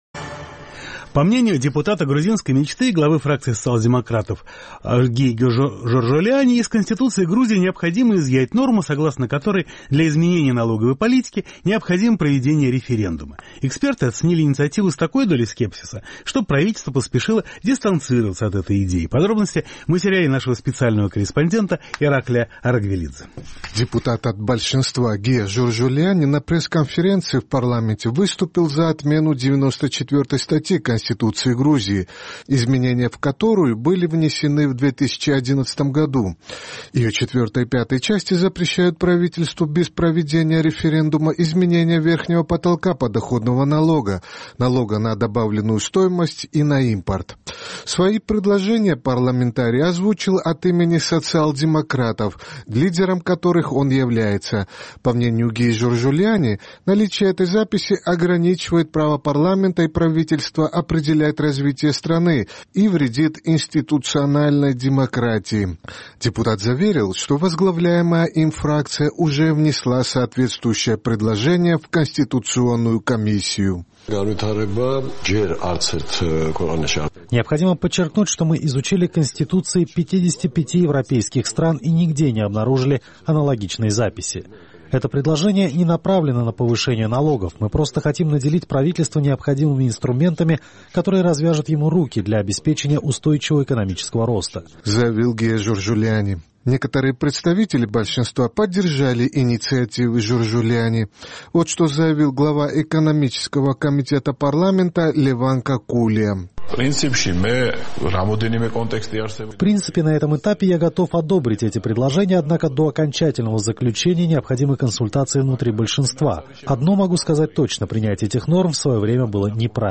Депутат от большинства Гия Жоржолиани на пресс-конференции в парламенте выступил за отмену 94-й статьи Конституции Грузии, изменения в которую были внесены в 2011 году.